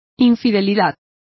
Complete with pronunciation of the translation of infidelity.